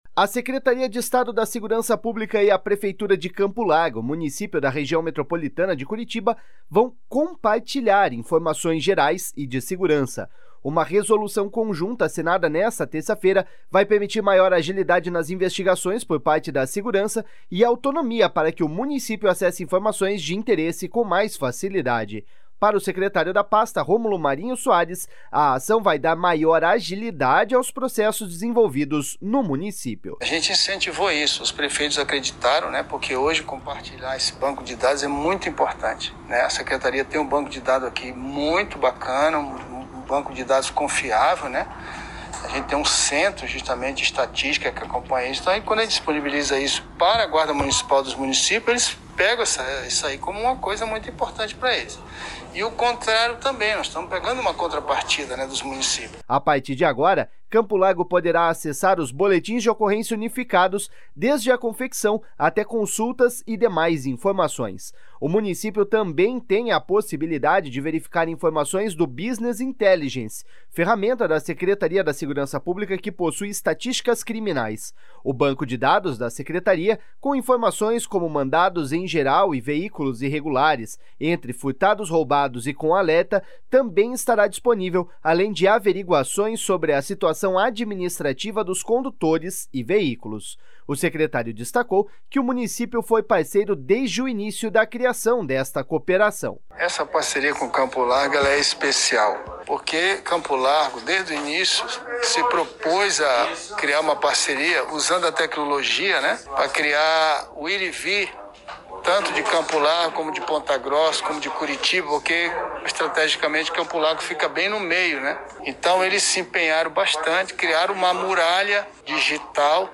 Uma resolução conjunta, assinada nesta terça-feira, vai permitir maior agilidade nas investigações por parte da Segurança e autonomia para que o município acesse informações de interesse com mais facilidade. Para o secretário da pasta, Romulo Marinho Soares, a ação vai dar maior agilidade aos processos desenvolvidos no município.// SONORA ROMULO MARINHO.//
O prefeito Marcelo Puppi ressaltou que a iniciativa é uma das mais importantes desenvolvidas em conjunto com o Governo do Estado.// SONORA MARCELO PUPPI.//